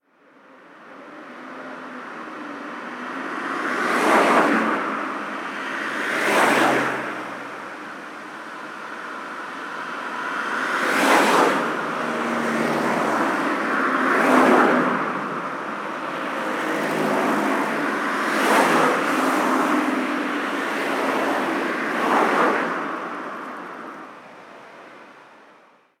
Ambiente de una carretera nacional
Sonidos: Transportes
Sonidos: Ciudad